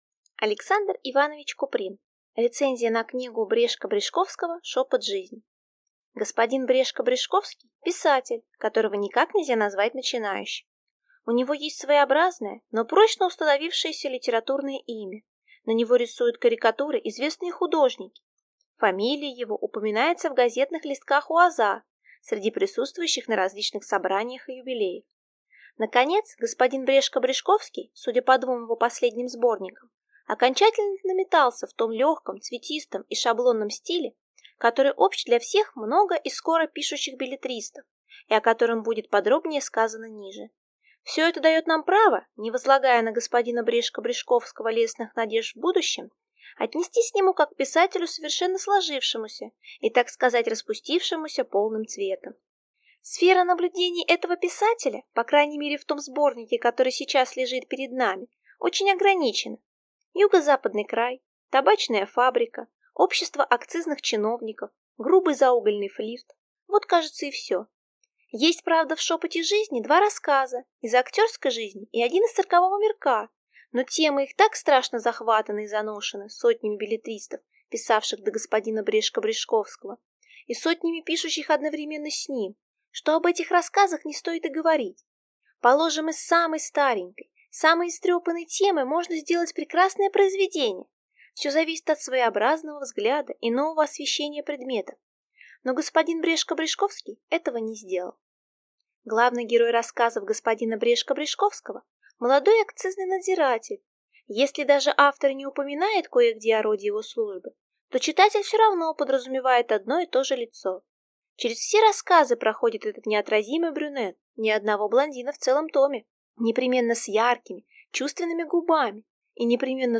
Аудиокнига Рецензия на книгу Н. Н. Брешко-Брешковского «Шепот жизни» | Библиотека аудиокниг